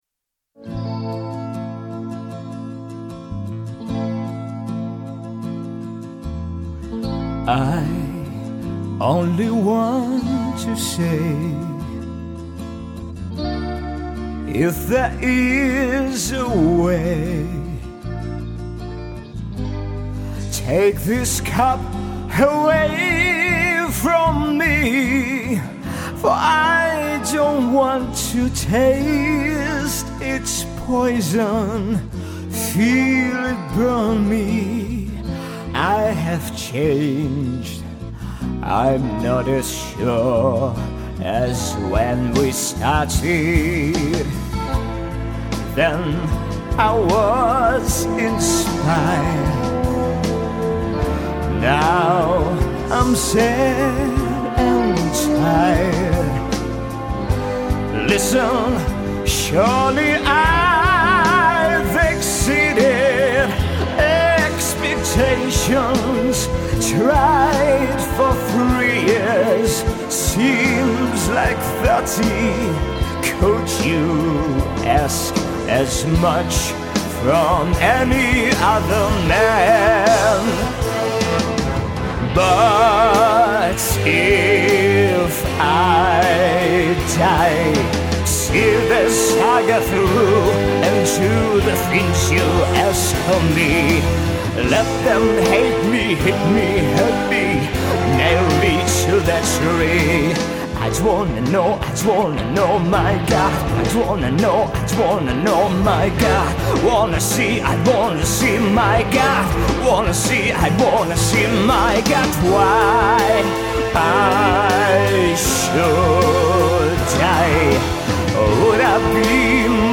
Мужской
Тенор Баритон